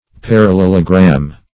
Parallelogram \Par`al*lel"o*gram\, n. [Gr.